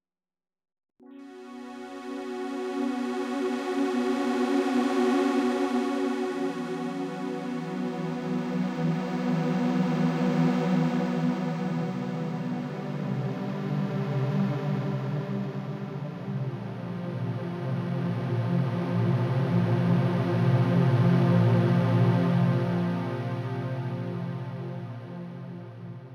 You will lose the high frequencies of the sample, but if you set the octave of the synth to low, and combine it with the unison function, it will be useful for creating pad sounds.
Unison Chord Sound from Tone2 Icarus(AM Saw Wave)